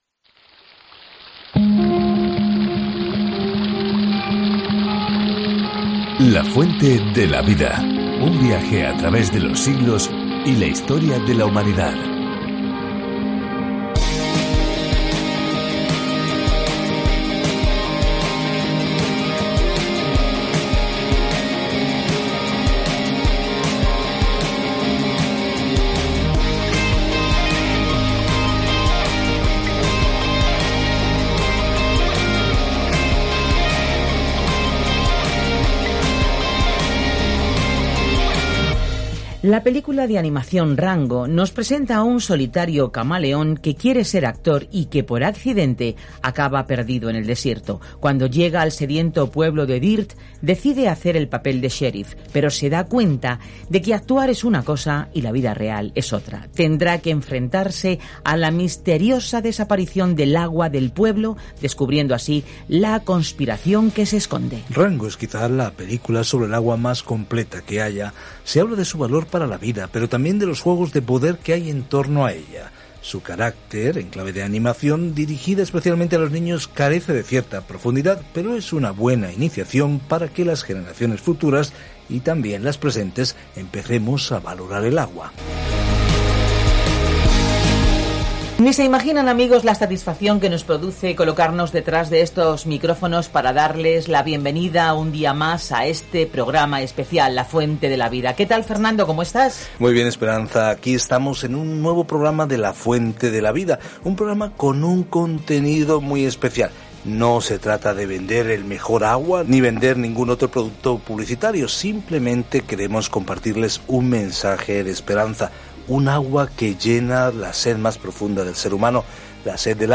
Escritura ECLESIASTÉS 4:10-16 ECLESIASTÉS 5:1-7 Día 5 Iniciar plan Día 7 Acerca de este Plan Eclesiastés es una autobiografía dramática de la vida de Salomón cuando intentaba ser feliz sin Dios. Al viajar diariamente a través de Eclesiastés, escucha el estudio de audio y lee versículos seleccionados de la palabra de Dios.